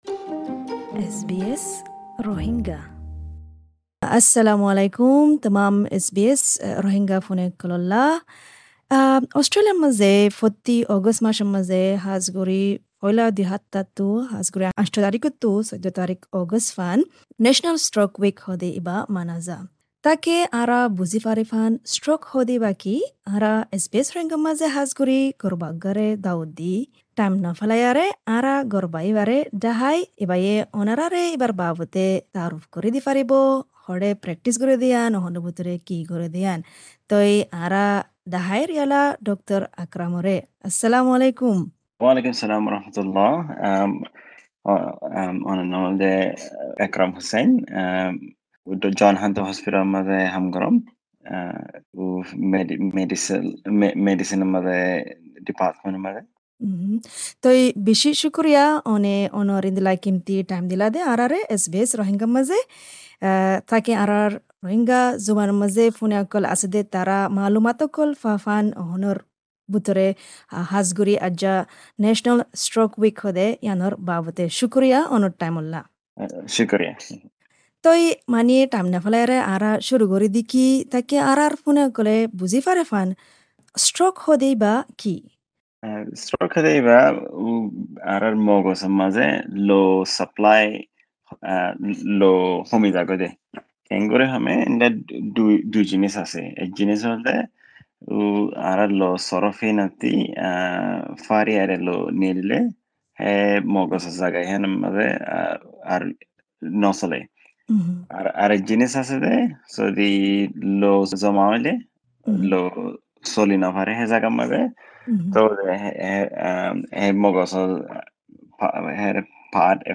Especial interview